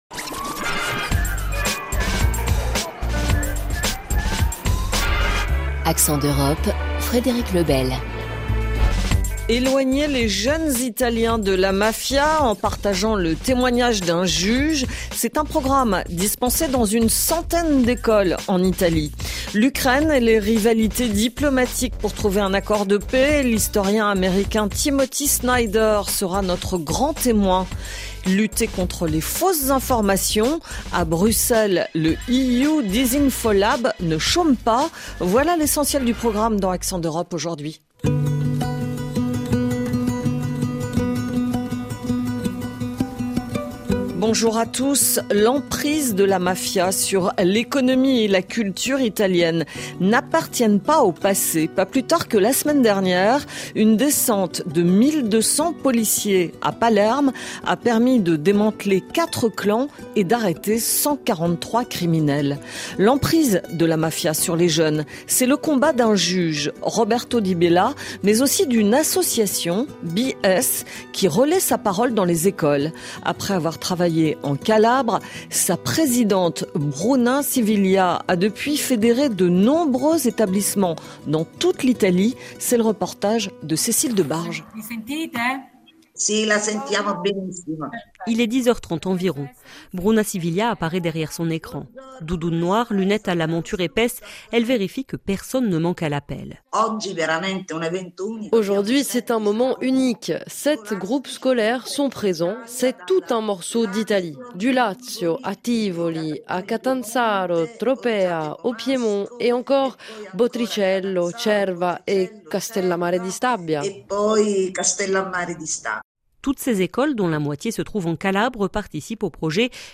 Tous les aspects de la vie quotidienne des Européens avec les correspondants de RFI.